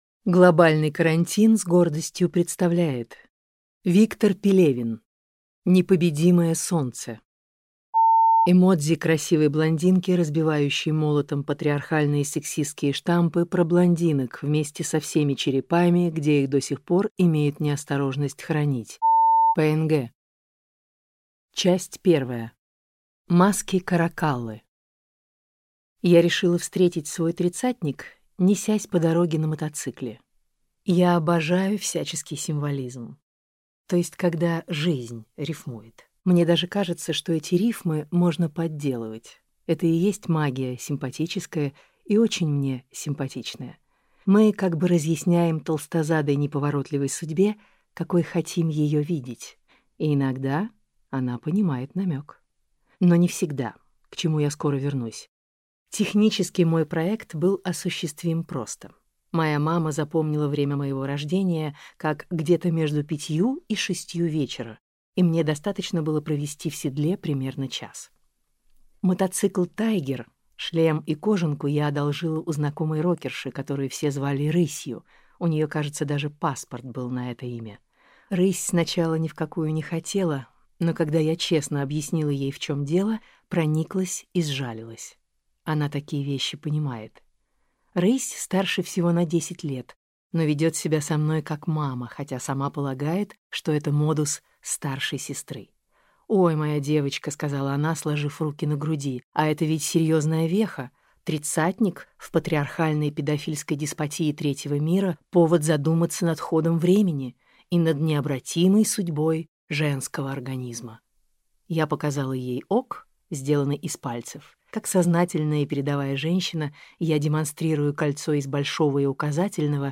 Аудиокнига Непобедимое солнце. Книга 1 - купить, скачать и слушать онлайн | КнигоПоиск